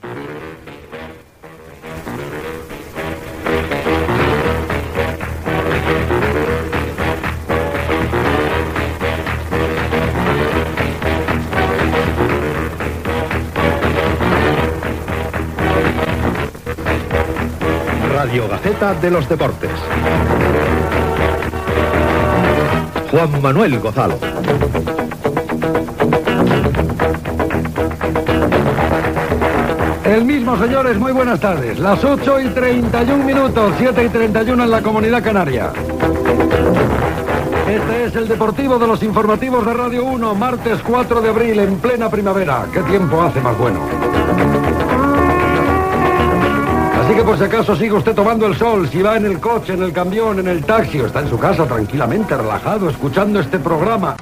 Careta, salutació, hora, data, i estat del temps
Esportiu
Gravació realitzada a València.